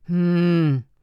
traf_hmm.wav